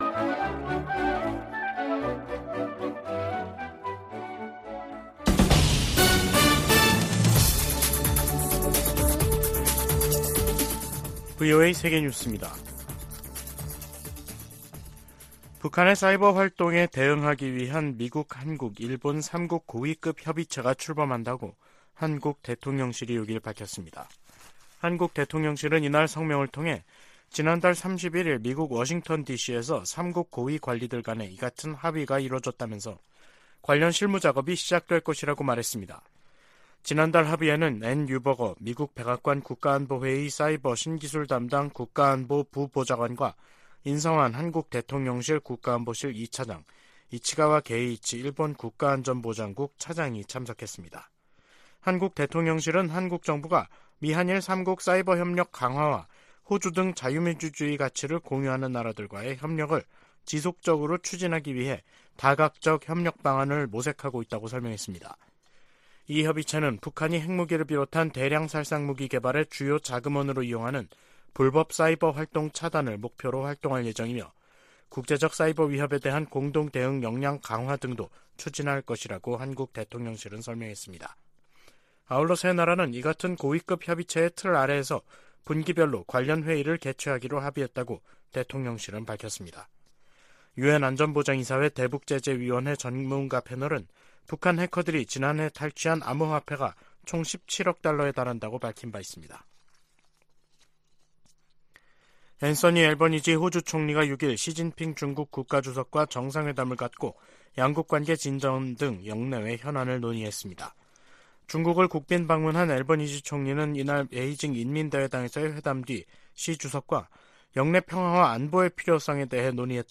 VOA 한국어 간판 뉴스 프로그램 '뉴스 투데이', 2023년 11월 6일 3부 방송입니다. 미 국방부는 북한이 미 대륙간탄도미사일 '미니트맨3' 시험발사를 비난한 데 대해 북한의 군사적 위협을 지적했습니다. 미 상원의원들이 북한과 러시아 간 군사 협력 확대가 전 세계를 위협한다며 단호한 국제적 대응을 촉구했습니다. 유엔 식량농업기구(FAO)는 17년재 북한을 외부의 식량 지원 필요국으로 지정했습니다.